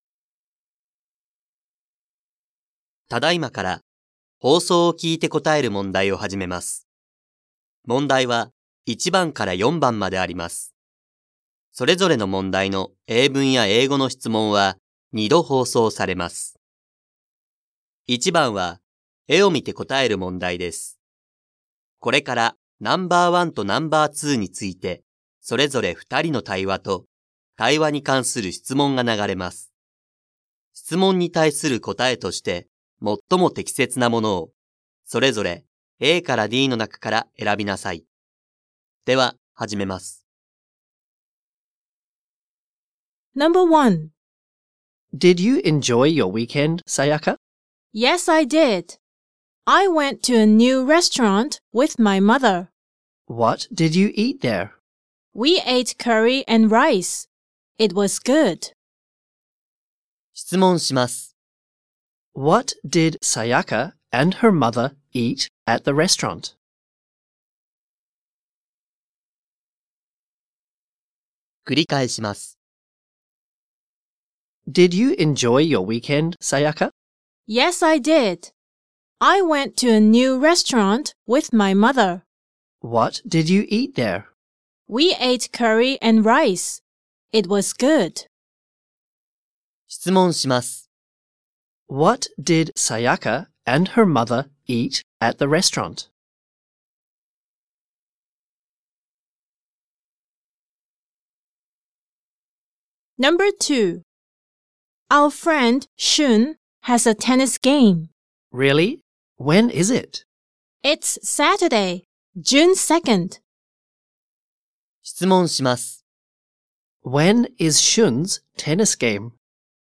2025年度１年３号英語のリスニングテストの音声